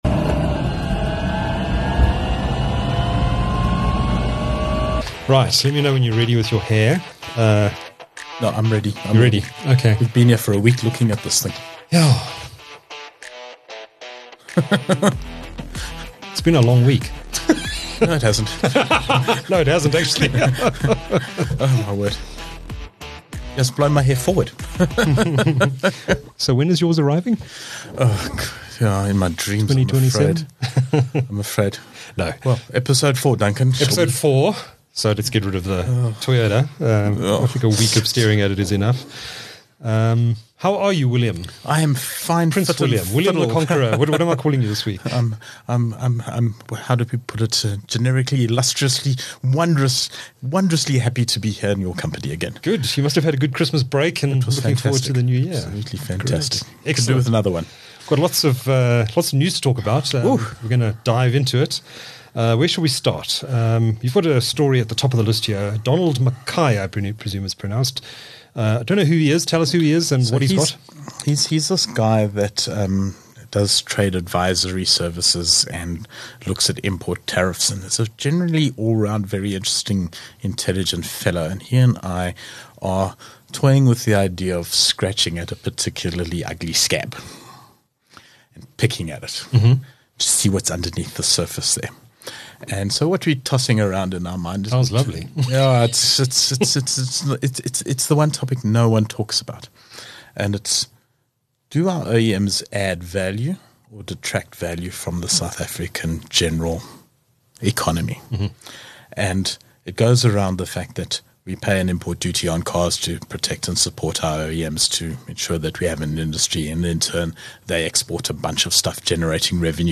The show features two review segments this week.